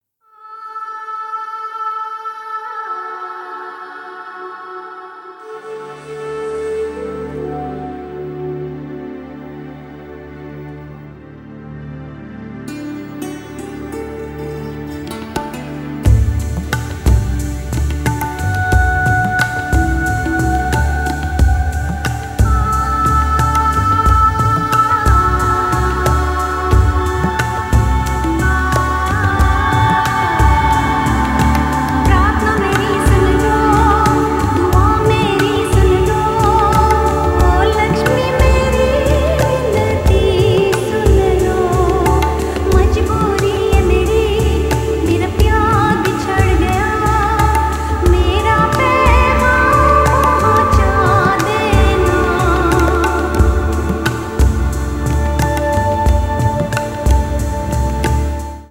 Tablas